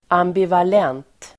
Uttal: [ambival'ent]